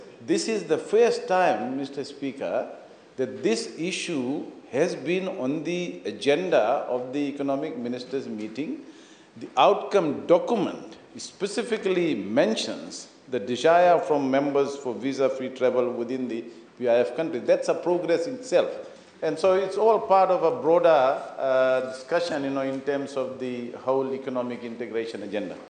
Minister for Finance Biman Prasad mentioned this when responding to the opposition’s query regarding updates on the proposed visa-free travel arrangement for Fijians to New Zealand and Australia.